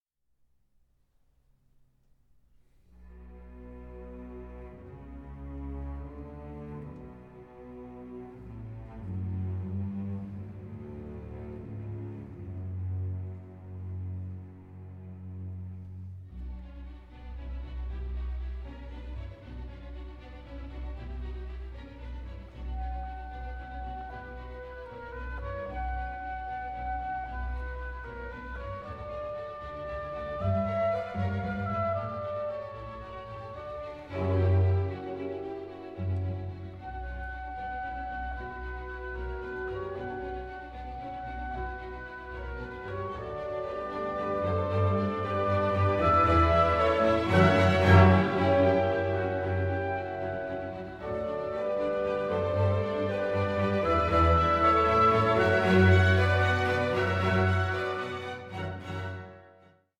PLAYED WITH VITALITY AND INTENSITY